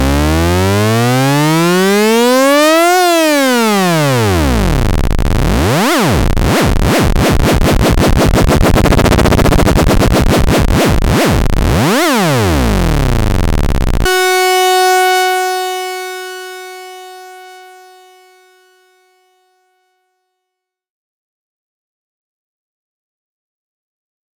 The LFO is pushed harder and creates a crazy pitch based effect.